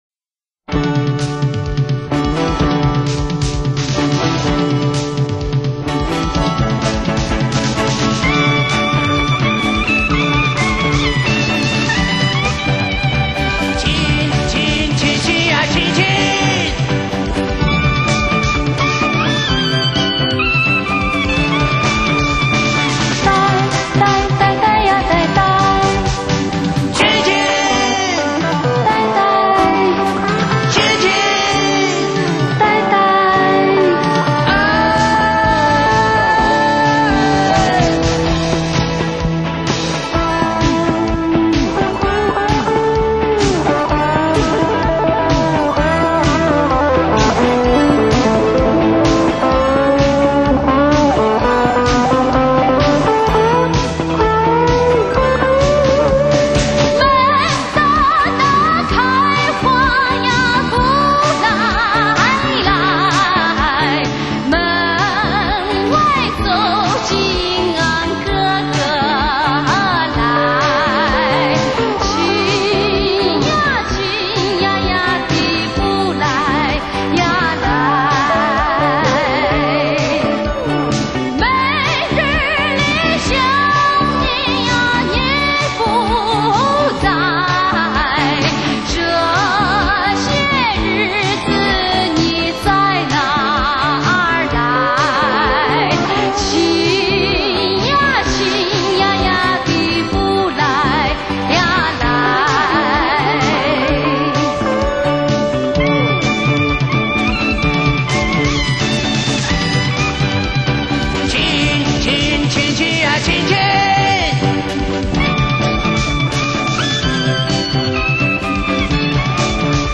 西部韵味和摇滚风格
具有浓郁的西部韵味和摇滚风格。